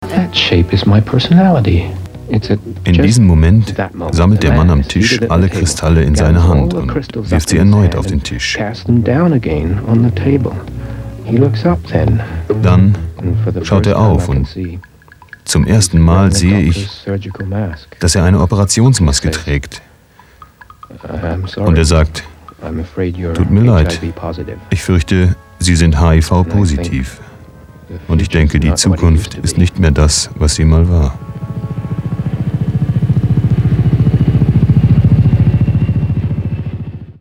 Kein Dialekt
Sprechprobe: Sonstiges (Muttersprache):
german voice over artist.